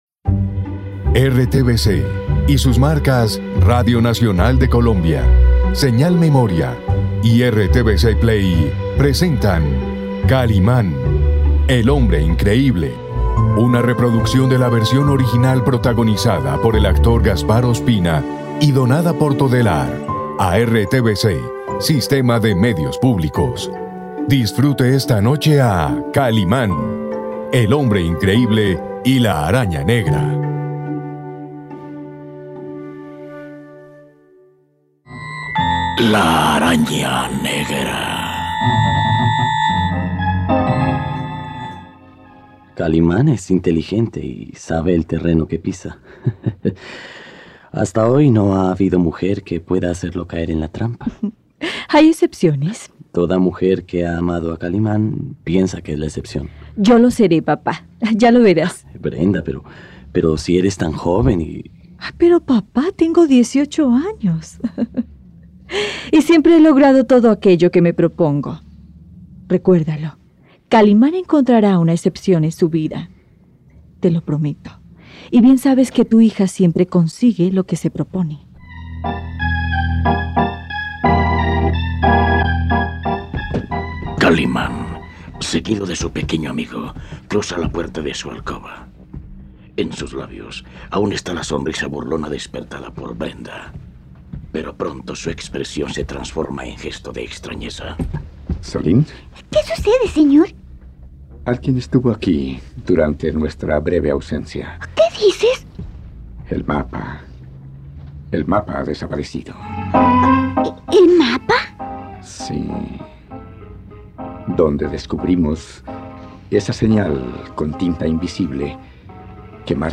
Kaliman Araña Negra Cap 06 Con promos.mp3